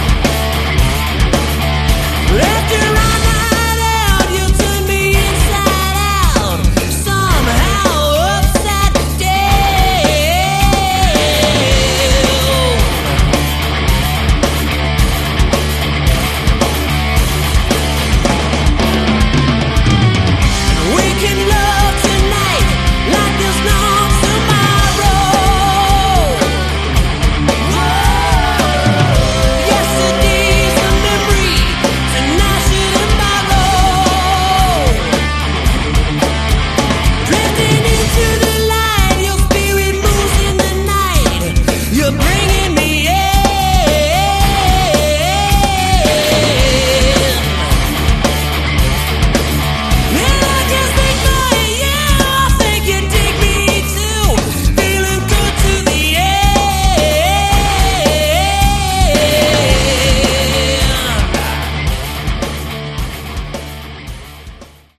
Category: Melodic Rock
Lead Vocals
Guitar
Bass Guitar, Vocals
Drums, Vocals